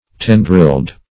Search Result for " tendrilled" : The Collaborative International Dictionary of English v.0.48: Tendriled \Ten"driled\, Tendrilled \Ten"drilled\, a. (Bot.)
tendrilled.mp3